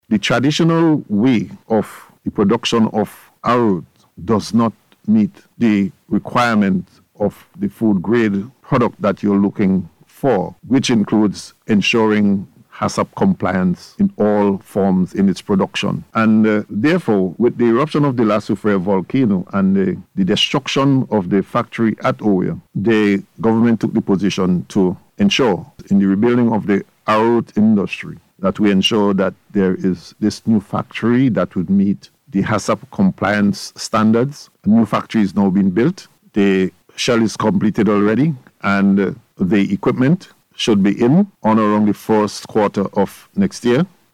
Meanwhile, speaking on NBC Radio’s Face to Face Radio programme, Acting Prime Minister Montgomery Daniel says the Arrowroot factory should be completed sometime in the first quarter of next year.